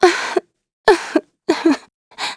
Xerah-Vox_Sad.wav